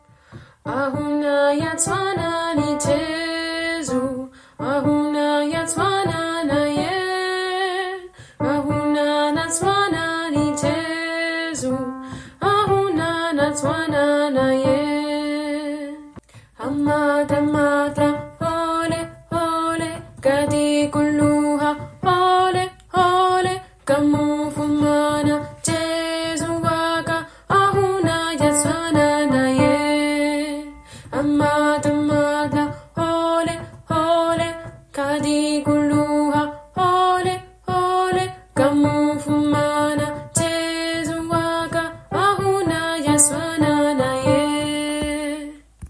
Alti